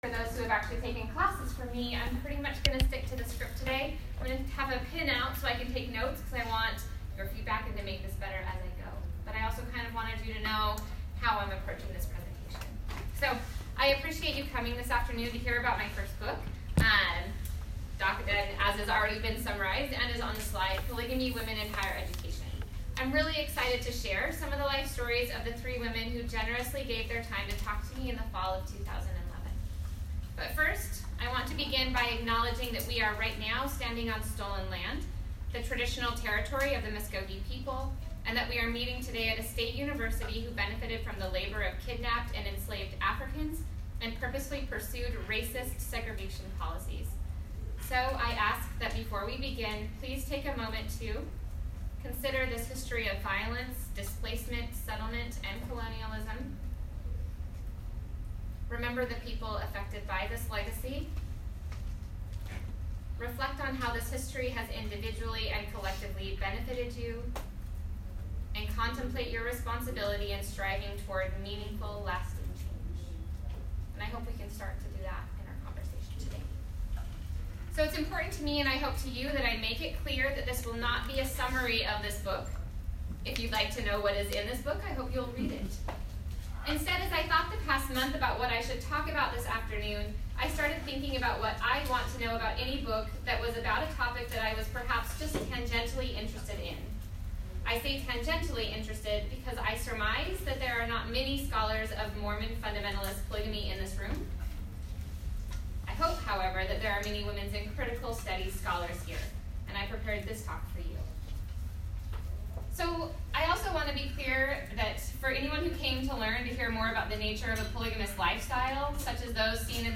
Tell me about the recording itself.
I’m sharing the text (listen to audio including Q&A in the link at the bottom of the page) of the book talk I gave at the Auburn Women’s Studies Speaker series today.